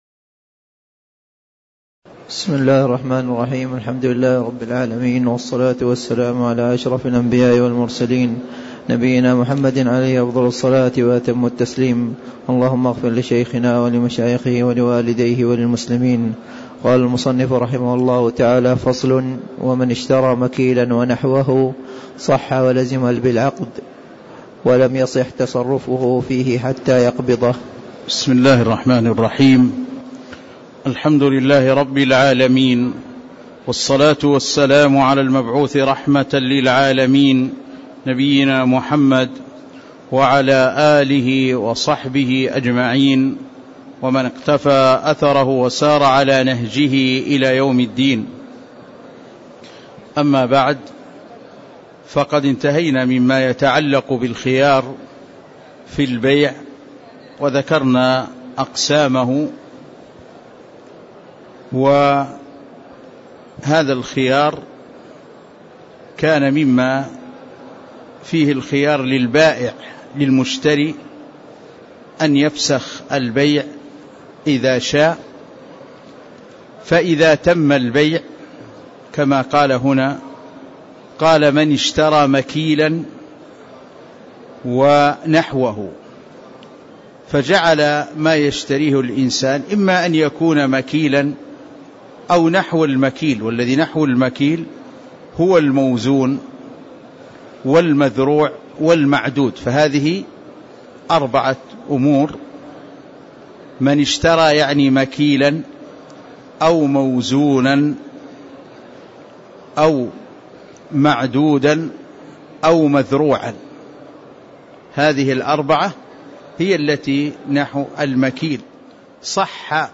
تاريخ النشر ١٤ رجب ١٤٣٦ هـ المكان: المسجد النبوي الشيخ